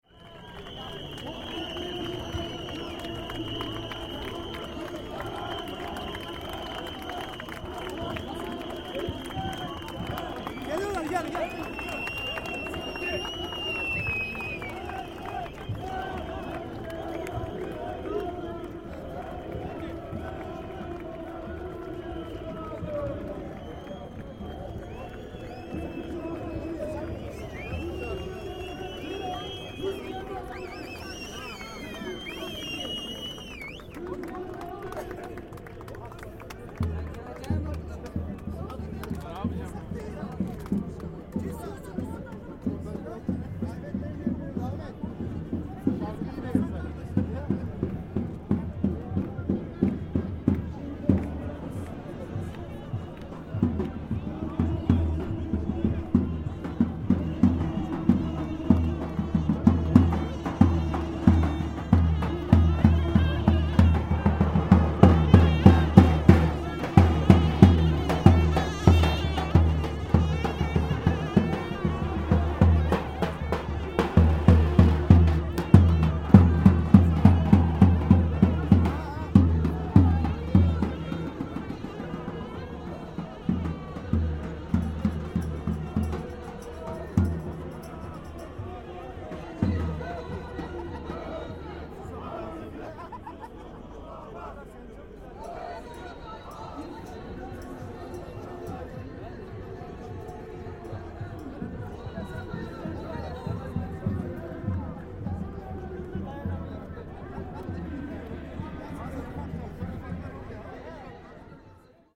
Soon enough the sound levels at the square are baffling.
A telling indicator of the roar of the huge set of loudspeakers dangling from cranes. (I am only able to record with the lowest recording level of my Edirol R-09, wearing earplugs at the same time.)
Not only audio though, acoustic sound sources were doing their best too. Here come the United Metal Workers with accompanying davul and zurna!